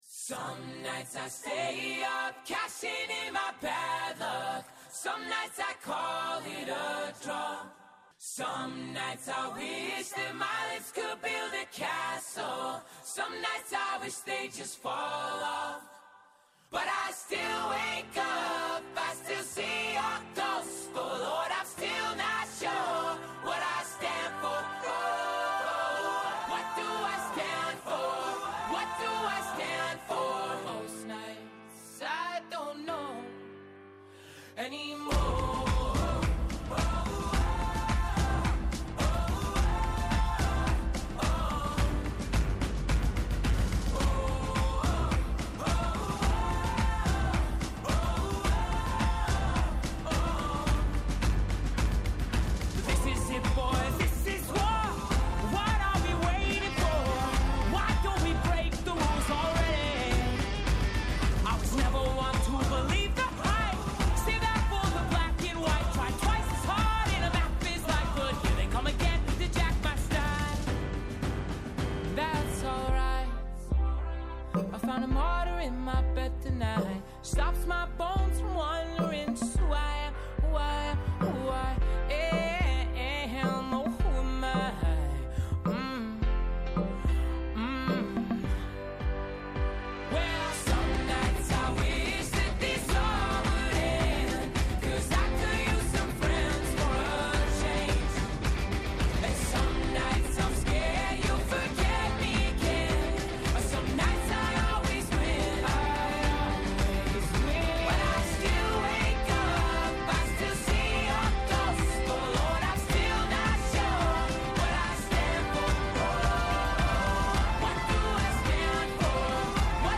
Στην σημερινή εκπομπή καλεσμένοι :
-Ο Δημήτρης Κούρκουλας, πρώην Υφυπουργός Εξωτερικών και πρώην Πρέσβης της Ευρωπαϊκής Ένωσης